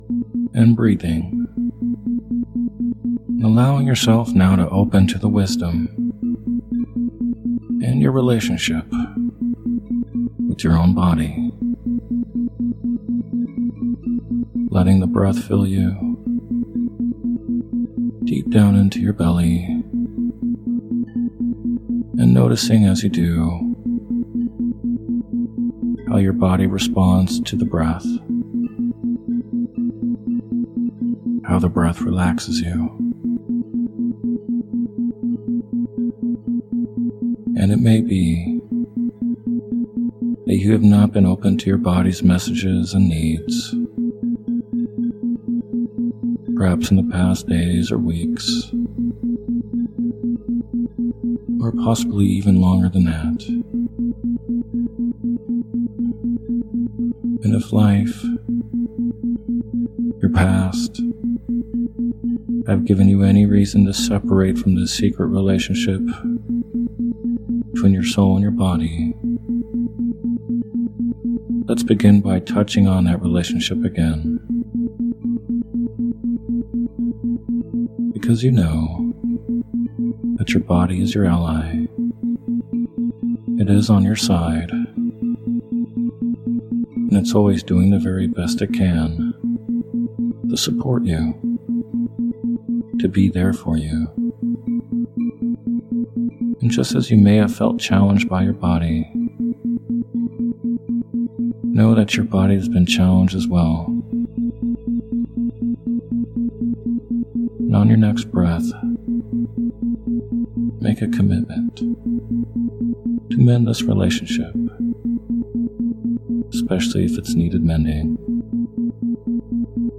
Sleep Hypnosis For Healing While Sleeping With Isochronic Tones